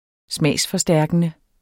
Udtale [ -fʌˌsdæɐ̯gənə ]